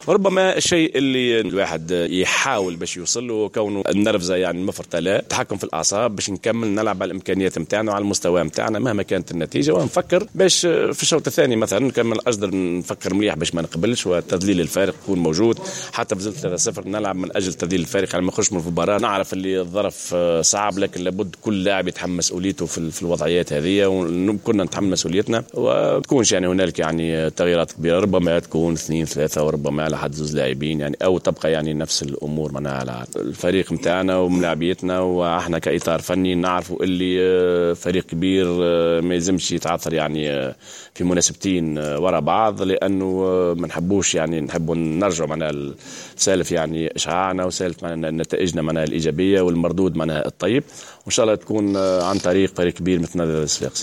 أكد مدرب الترجي الرياضي التونسي عمار السويح خلال الندوة الصحفية التي عقدها اليوم للحديث حول مباراة الكلاسيكو التي ستجمع الترجي الرياضي بالنادي الصفاقسي يوم الأحد 27 ديسمبر 2015 بداية من الساعة 14:00.